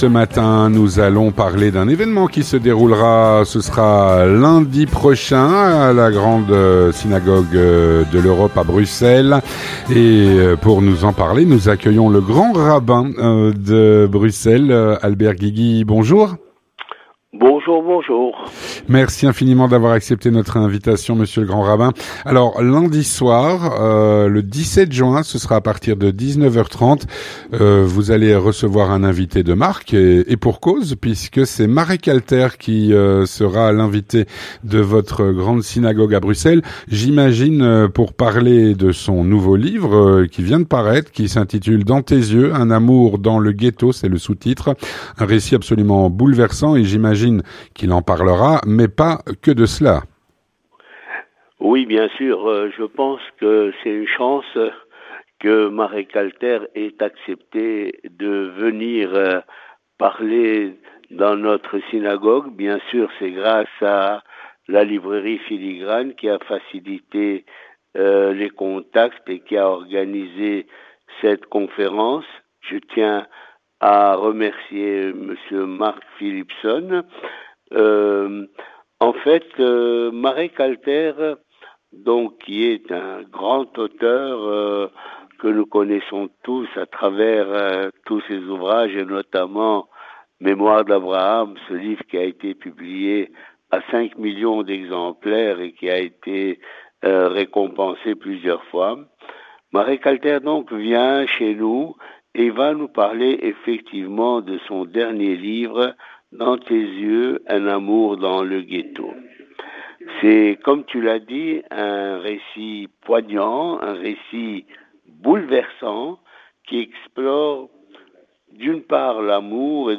Avec le Grand Rabbin de Bruxelles, Albert Guigui.